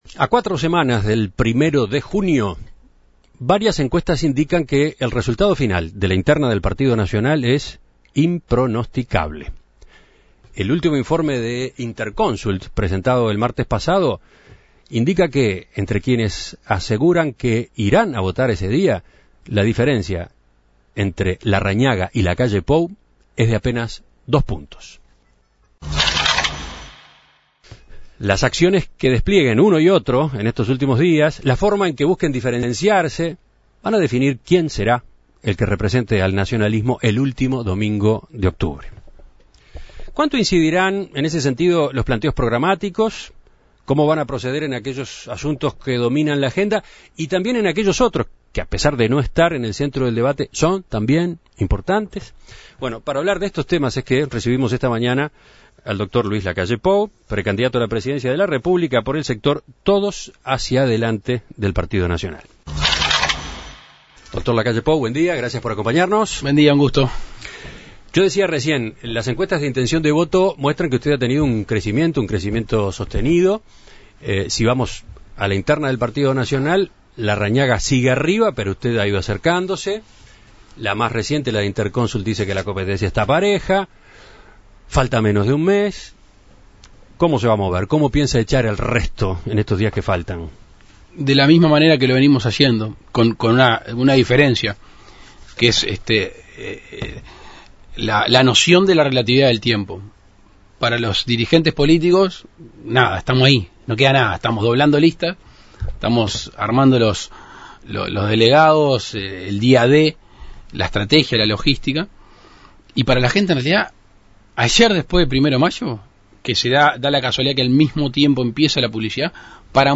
En Perspectiva consultó al precandidato del sector Todos Hacia Adelante para conocer sus propuestas en caso de ser electo. Desde el inicio de su campaña, Lacalle Pou viene señalando que su ministro de Educación sería el más importante del gabinete. En esta oportunidad adelantó el nombre que tiene en mente: Pablo da Silveira.